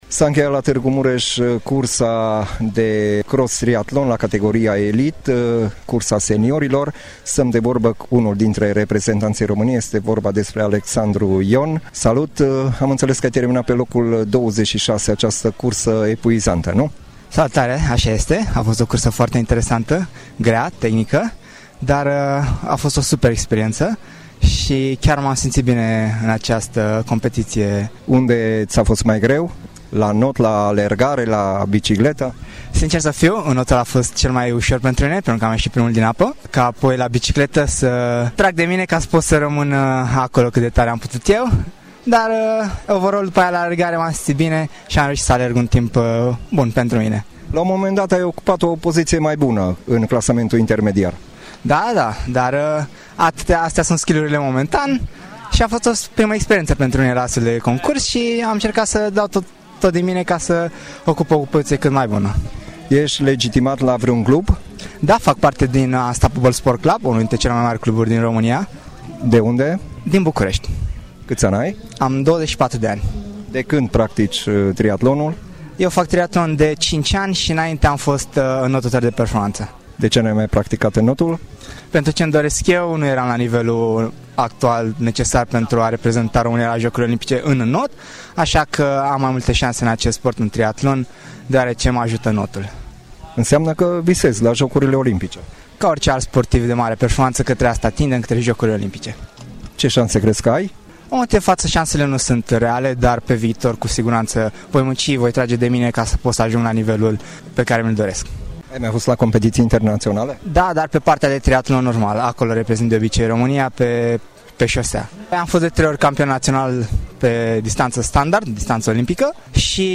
Interviu audio